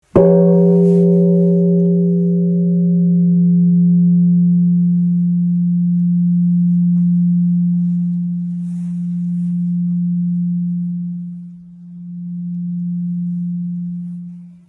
十輪寺梵鐘 ～雨乞いの鐘～
bonsho.mp3